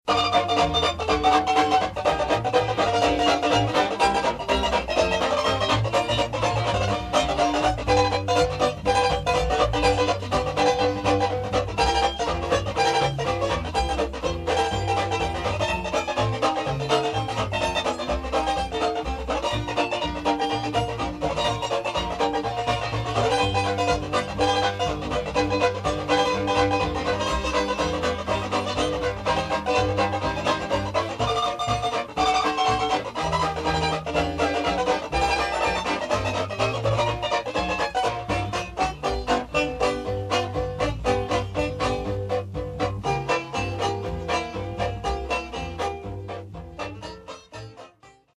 second banjo
string bass